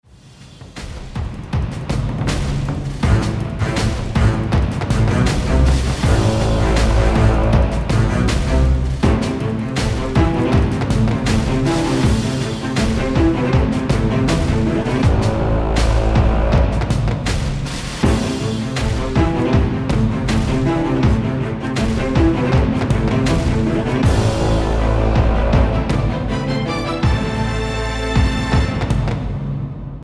• Качество: 320, Stereo
Electronic
без слов
electro